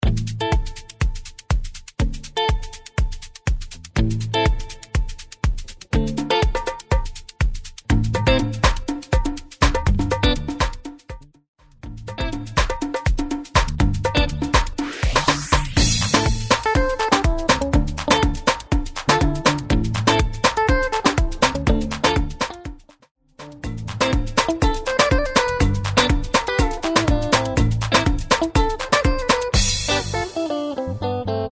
122 BPM
Upbeat World